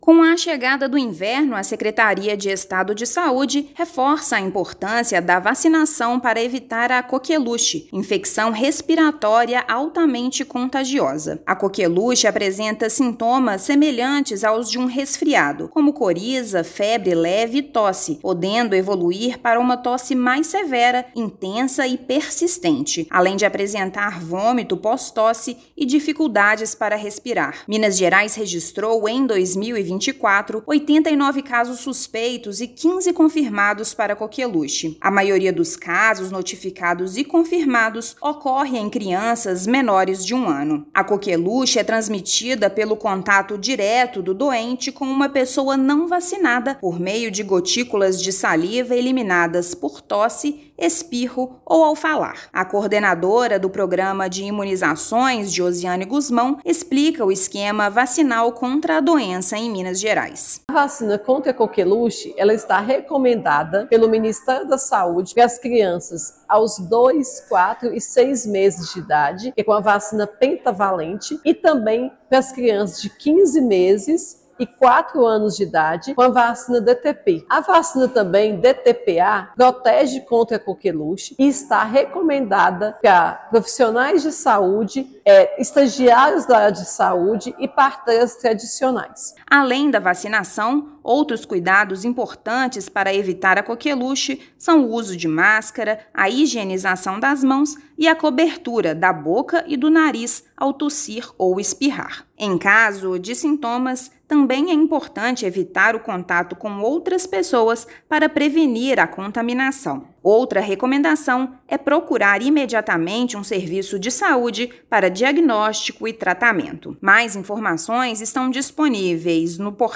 [RÁDIO] Vacinação é a principal forma de prevenção à coqueluche
Imunização é a medida mais eficaz e adequada para prevenir e controlar a doença, principalmente, na população infantil. Ouça a matéria de rádio: